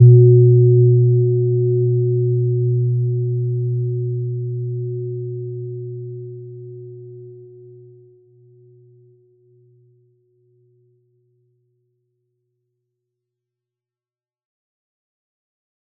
Gentle-Metallic-1-B2-p.wav